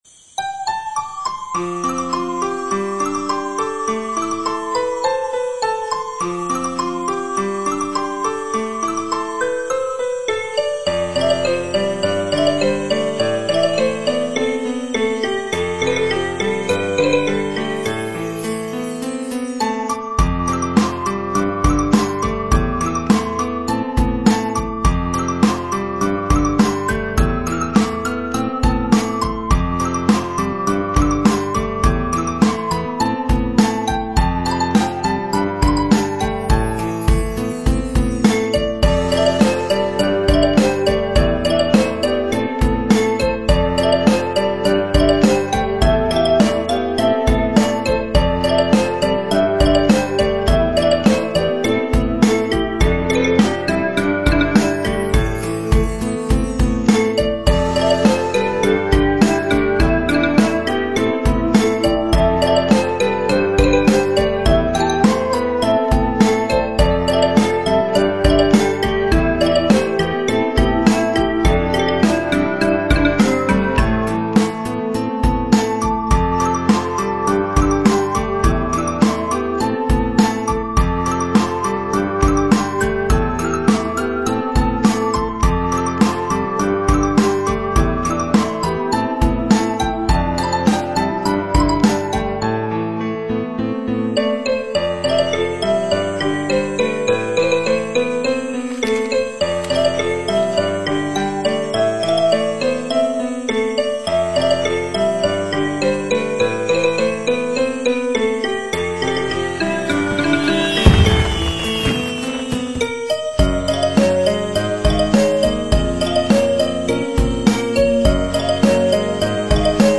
轻音乐纯音乐